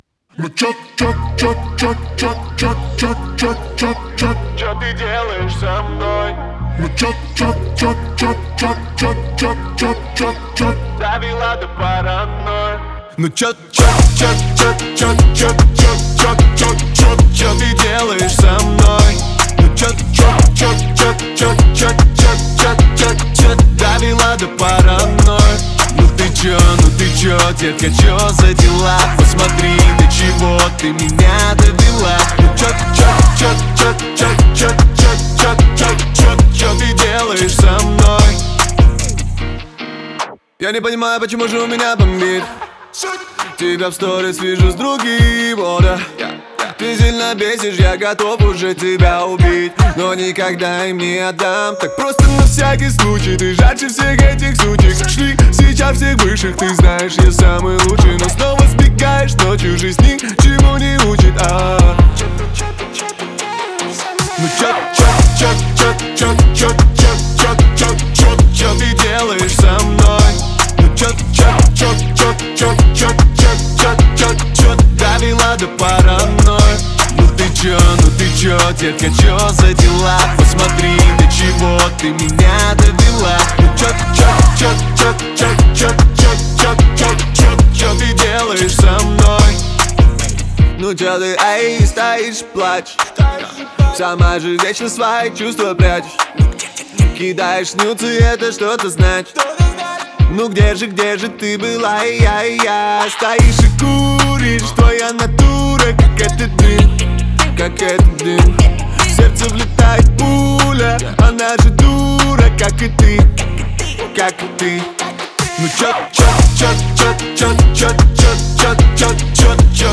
в жанре поп-рок